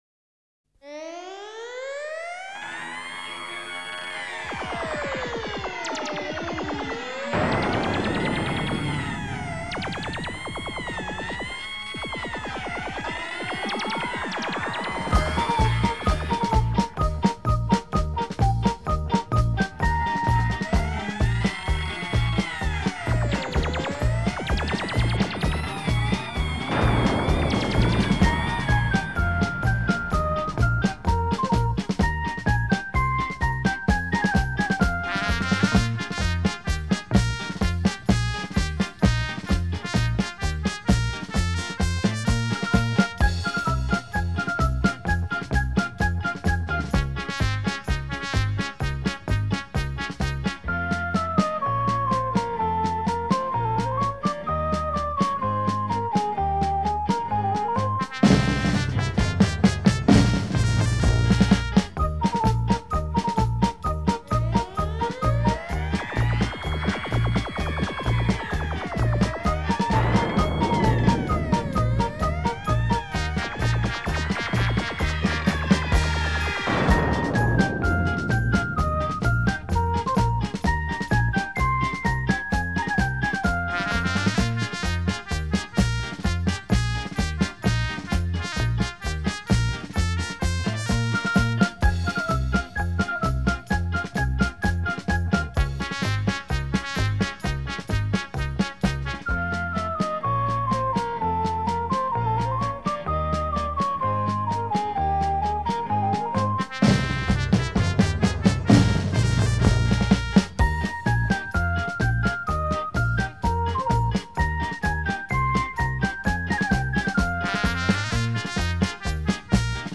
轻音乐进行曲
进行曲是一种用节奏写成的乐曲，富有步伐节奏。
雄劲刚键的旋律和坚定有力的节奏是进行曲的基本特点。